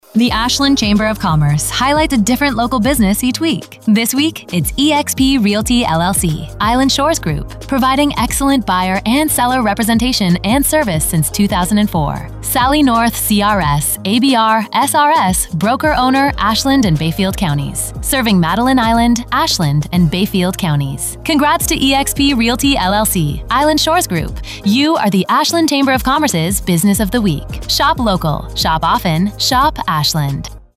Ashland Chamber’s Business of the Week for July 6 , 2020: Island Shores Group eXp Realty, LLC Each week the Ashland Chamber of Commerce highlights a business on Heartland Communications radio station WATW 1400AM. The Chamber draws a name from our membership and the radio station writes a 30-second ad exclusively for that business.